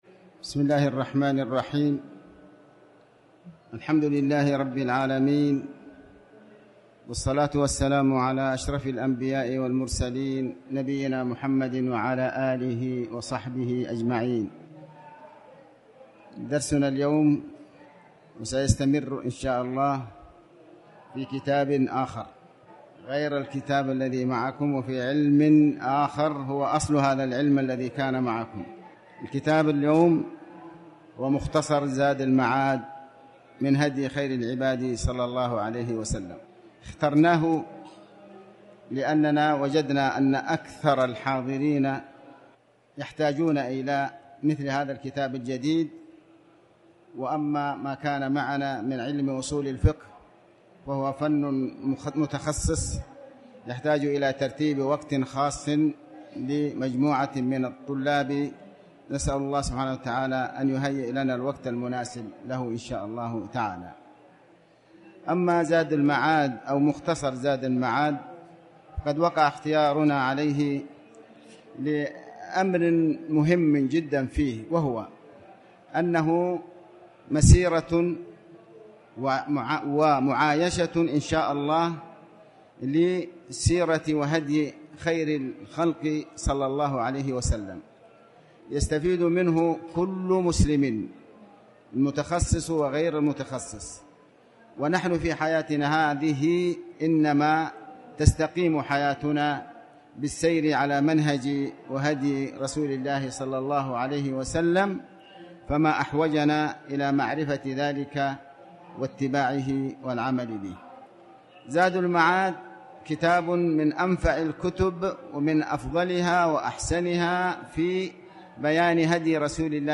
تاريخ النشر ١٦ محرم ١٤٤٠ هـ المكان: المسجد الحرام الشيخ: علي بن عباس الحكمي علي بن عباس الحكمي مقدمة الكتاب